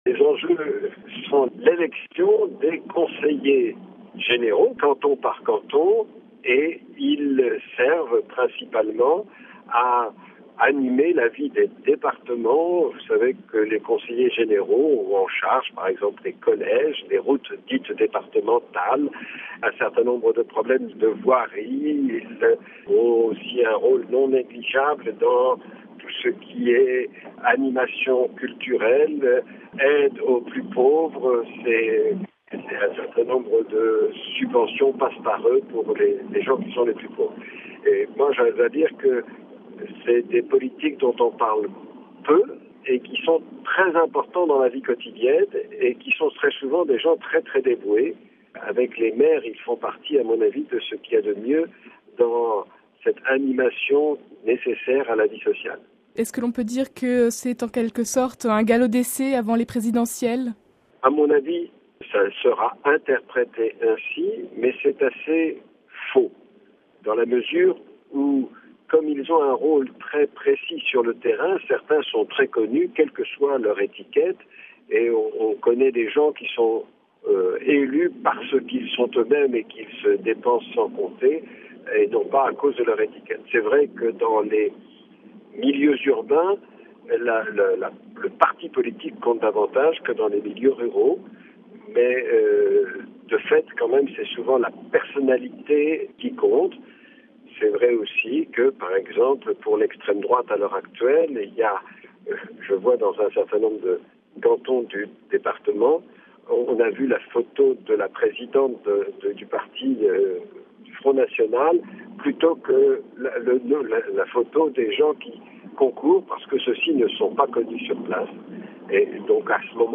Élections cantonales : Entretien avec Mgr Dubost